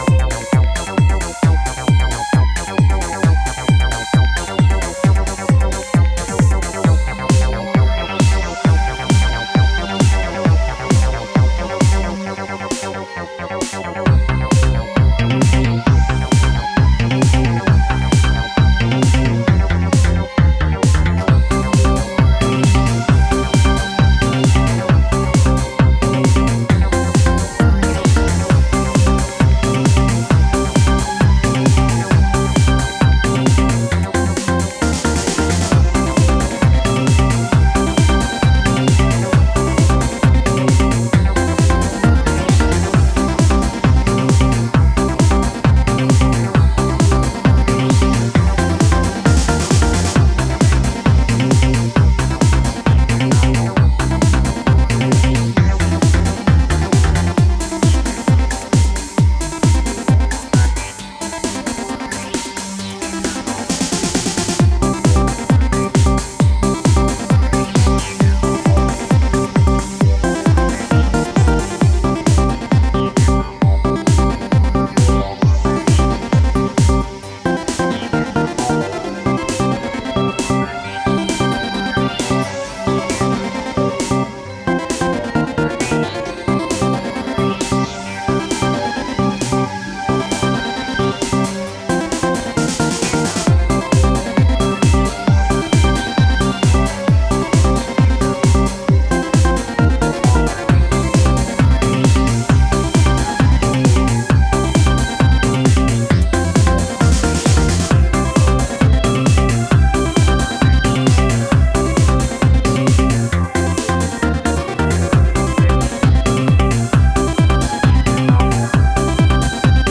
with an energetic and natural flow.
Characterized by the rhythmic energy of club music.